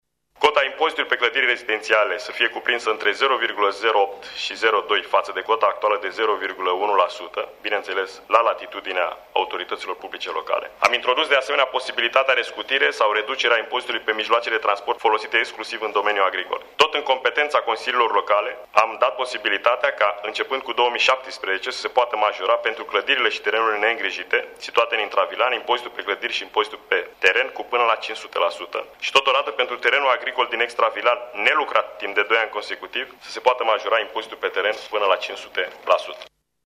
Ministrul Darius Vâlcov a precizat că autorităţile din teritoriu îşi vor putea stabili taxele ţinând cont şi de nevoile bugetare locale: